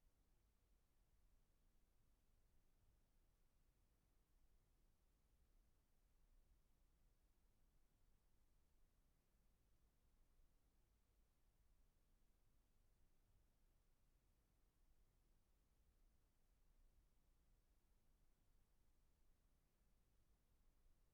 Vox Volubilis | Voix off française
Studio professionnel privé - home studio.